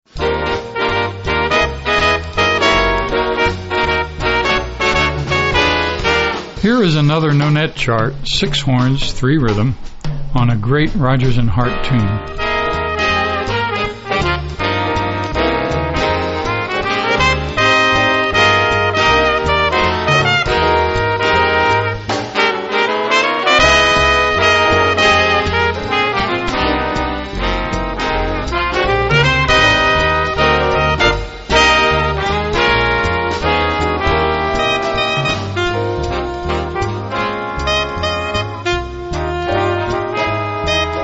Voicing: Little Big Band